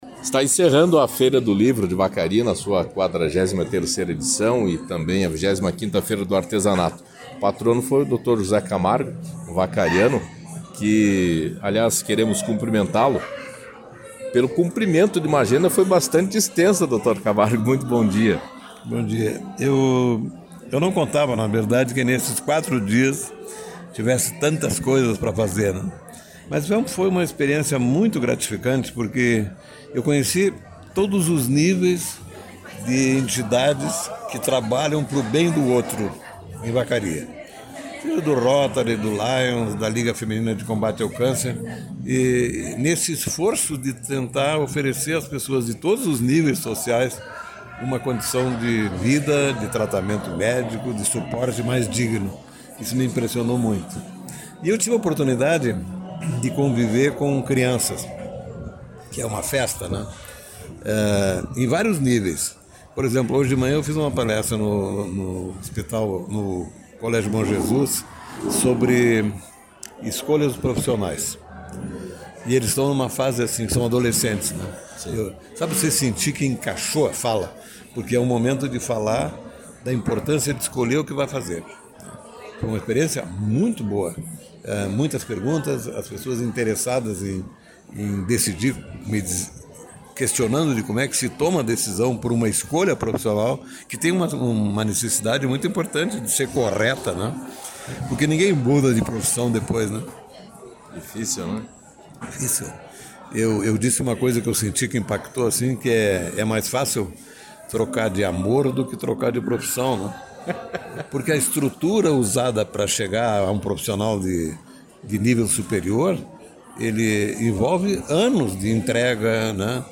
No dia 21 de outubro, no encerramento da Feira do Livro e do Artesanato de Vacaria, o patrono da feira, médico José Camargo, concedeu entrevista à Rádio Esmeralda falando dessa experiência que teve na sua terra durante cinco dias.